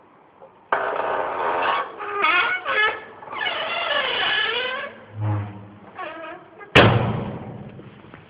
鱿鱼玩具
描述：一个发出吱吱声的狗玩具
标签： 吱吱声 吱吱响 玩具
声道立体声